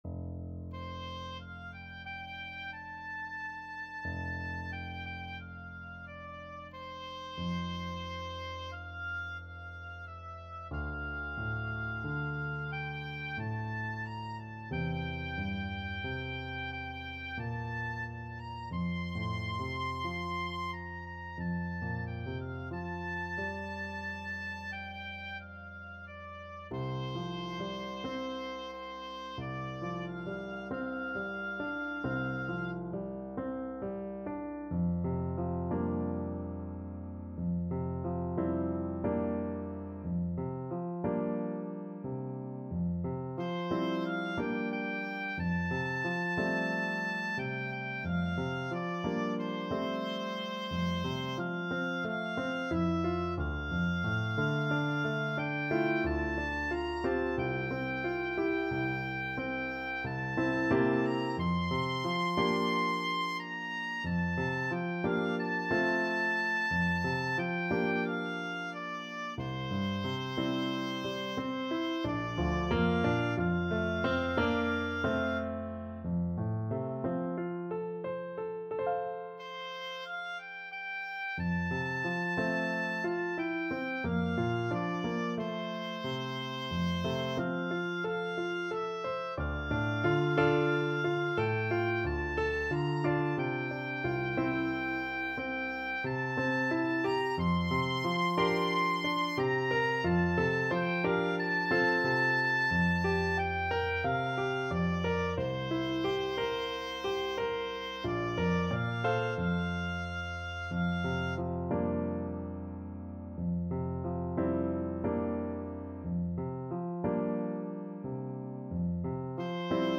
Oboe
F major (Sounding Pitch) (View more F major Music for Oboe )
4/4 (View more 4/4 Music)
Andante cantabile = c. 90
Traditional (View more Traditional Oboe Music)